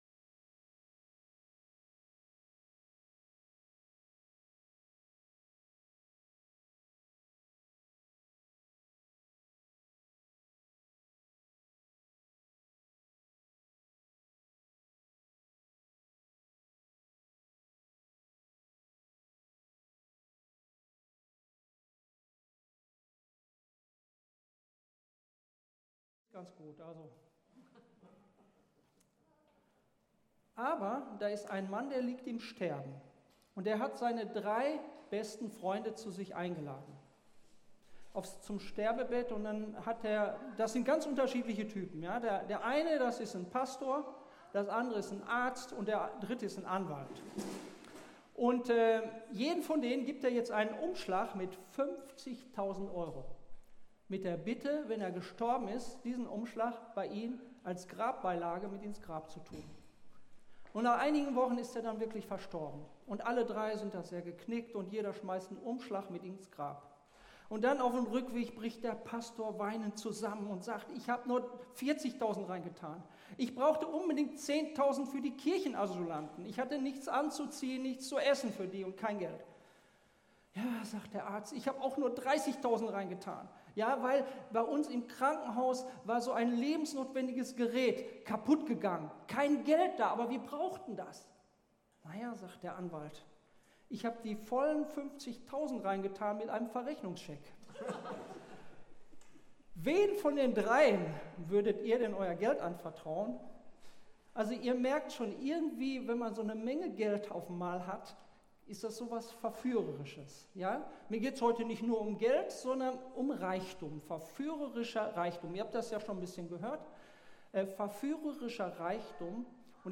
Passage: Psalm 73 Dienstart: Gottesdienst